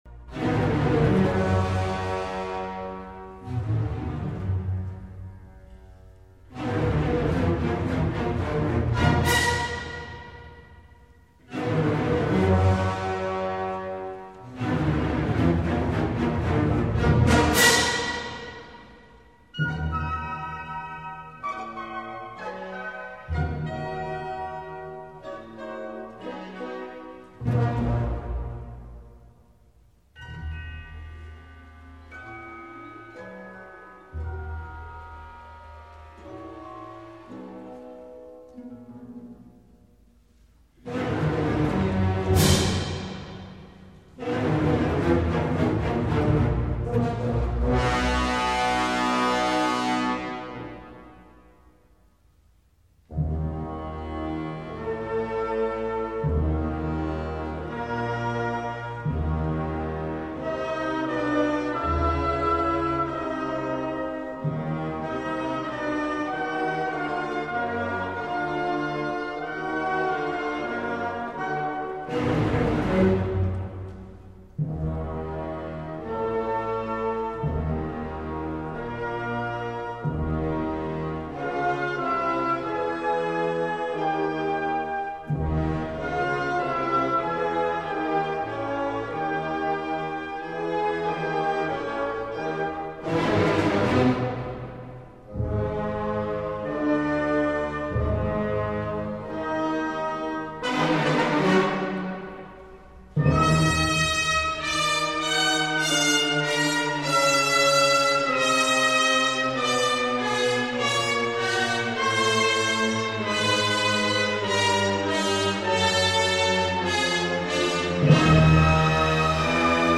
Будет слушать разные исполнения одного и того же произведения.